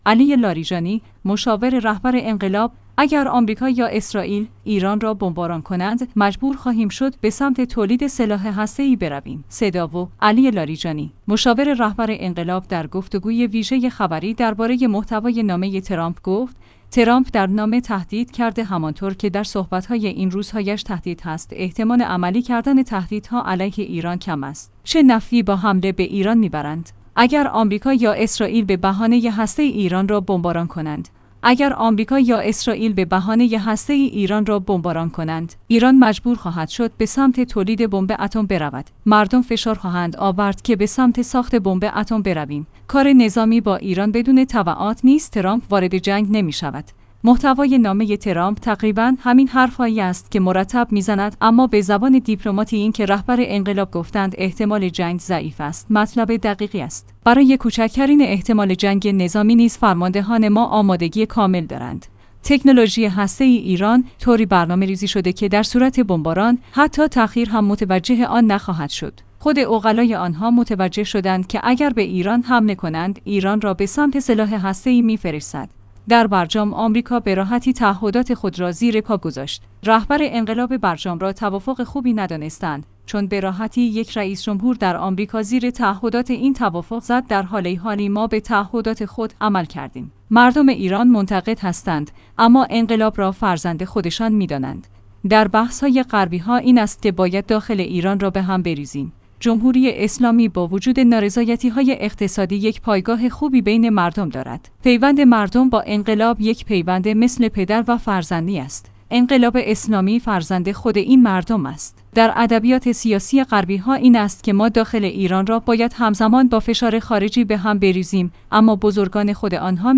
صدا و سیما/ علی لاریجانی، مشاور رهبر انقلاب در گفتگوی ویژه خبری درباره محتوای نامه ترامپ گفت: ترامپ در نامه تهدید کرده همانطور که در صحبت‌های این روزهایش تهدید هست؛ احتمال عملی‌کردن تهدیدها علیه ایران کم است، چه نفعی با حمله به ایران می‌برند؟